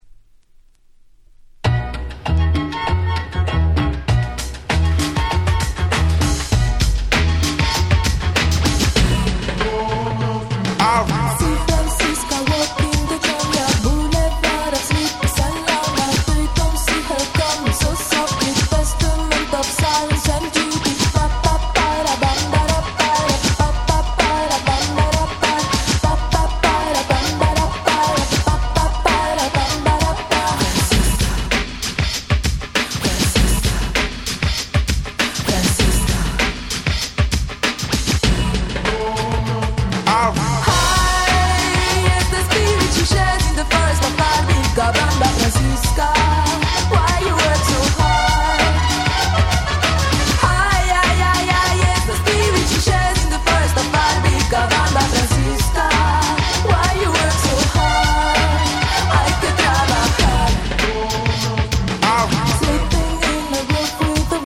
UK出身の美人女性シンガーでシングルは93年からリリースしておりました。
Crossoverな層に受けそうな非常にオシャレな楽曲ばかりです！
Bossa